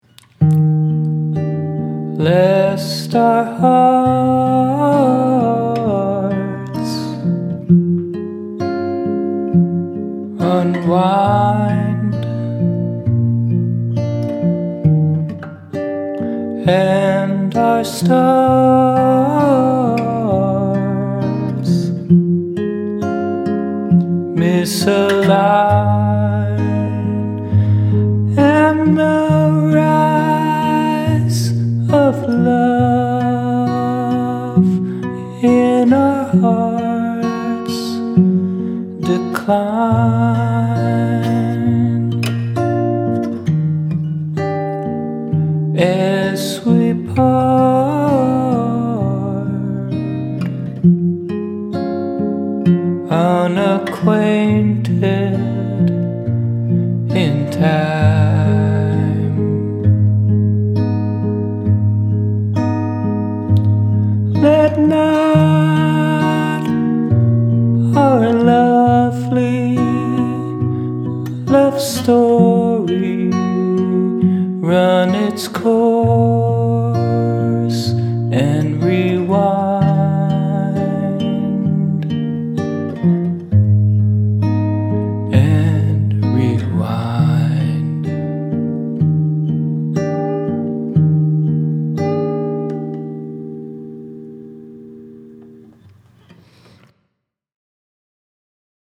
verse: Cmaj7, Am7, Bflat/D, G, Cmaj7, Am7, Bflat/D, Fm
bridge: Cm, Aflat, Eflat, G
half-verse: Cmaj7, Am7, Bflat/D, G, Fm
outro: Cmaj7, Fm, Cmaj7
more fun with modulation. i wrote the chords around 7pm tonight when i finished work. i was still messing around with modulating between Aminor/Cmajor and Cminor/Eflat major. For the words I did a little free writing and eventually hit on the word decline, then stars misalign, then the idea for a love story in reverse. once i had that in mind the words came pretty quickly. this performance is pretty shaky, but its late and i feel bad playing the song anymore tonight.